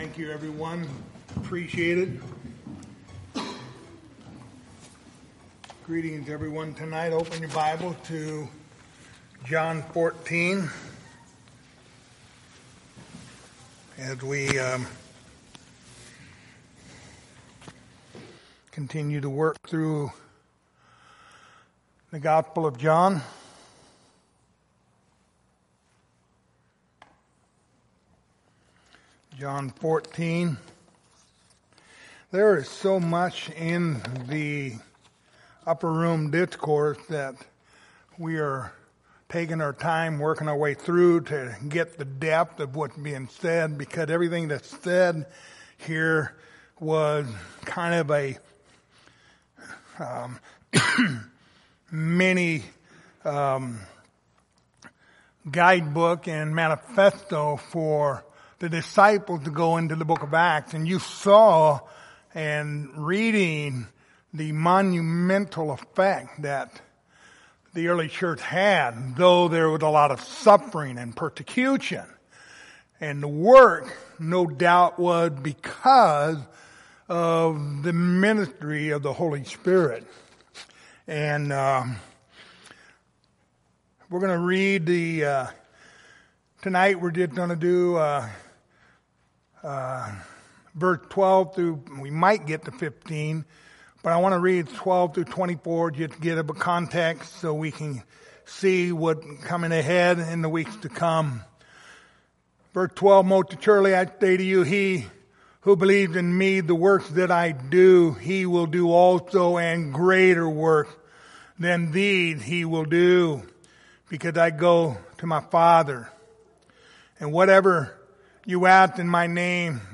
The Gospel of John Passage: John 14:12-15 Service Type: Wednesday Evening Topics